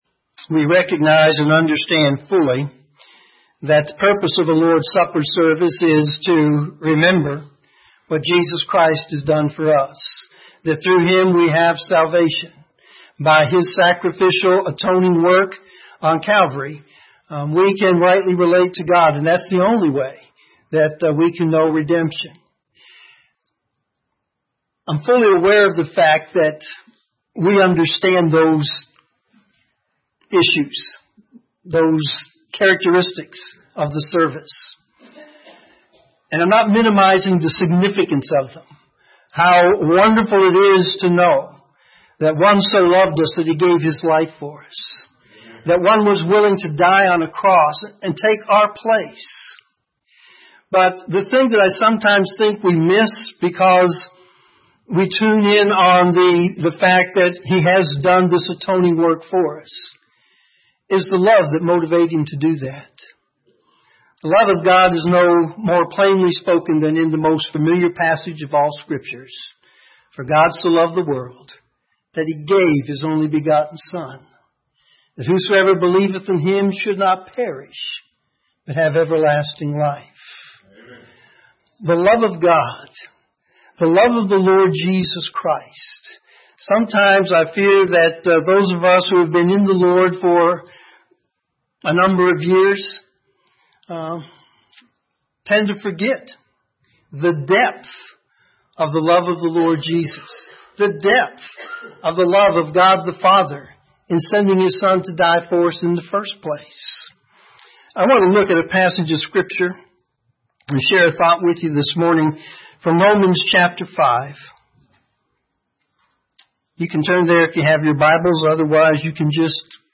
Morning Sermon
sermon7-3-16.mp3